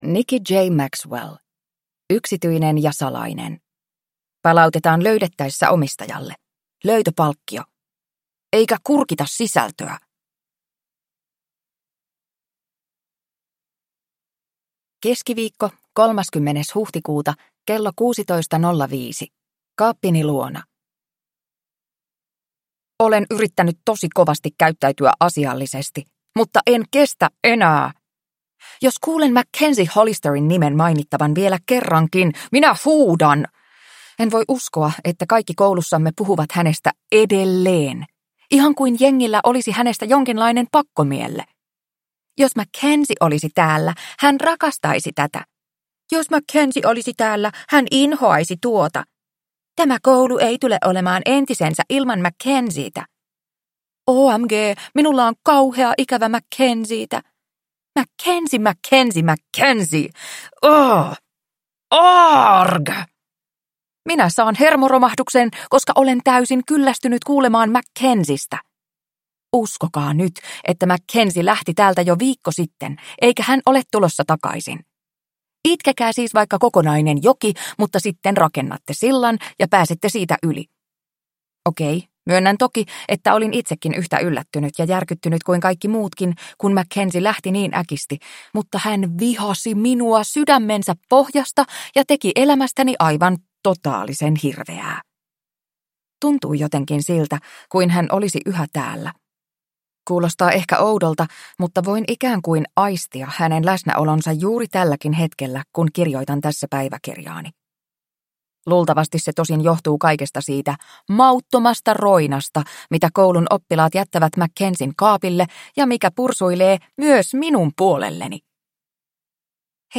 Nolo elämäni: Olisinpa pentuvahti – Ljudbok – Laddas ner